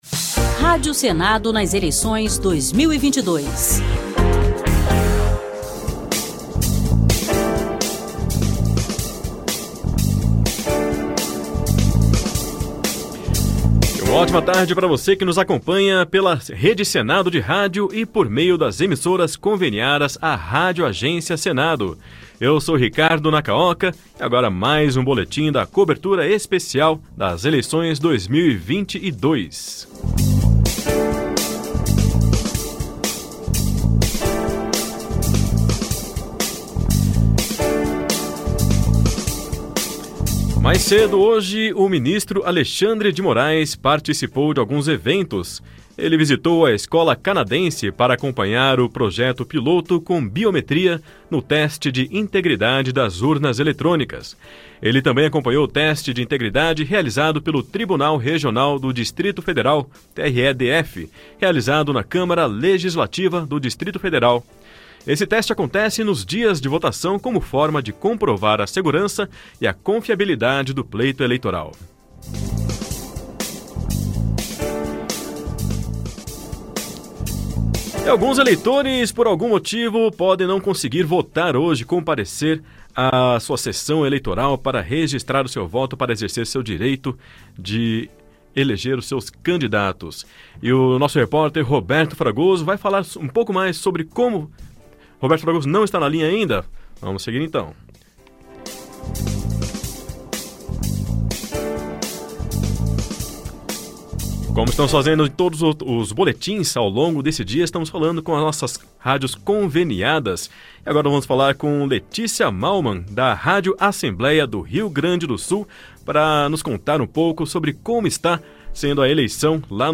Boletim Eleições 2022 – 7ª edição